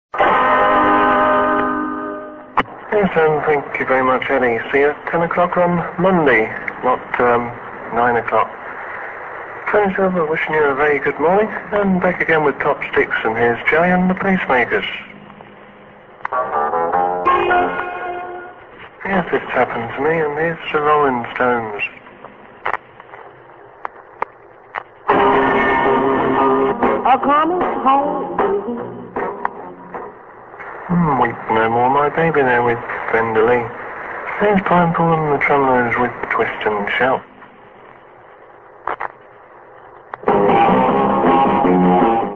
on Radio Invicta's Top Sticks programme